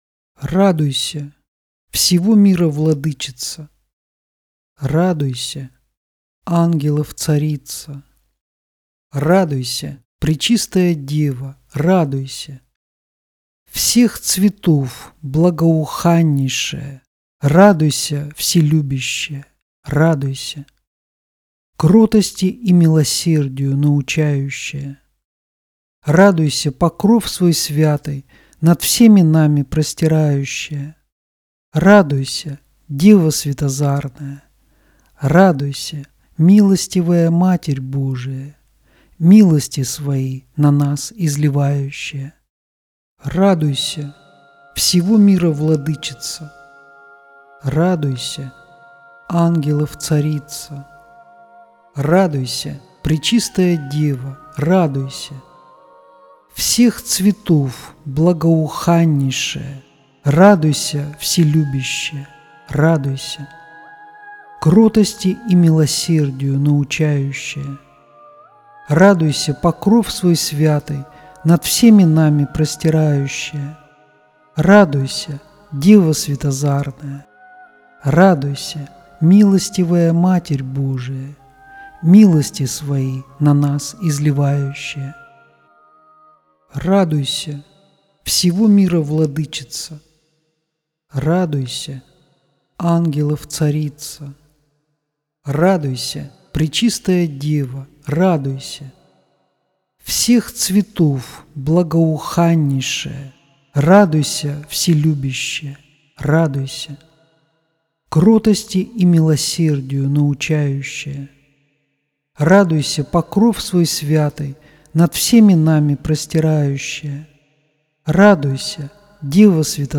Молитва «Богородица» (33 раза)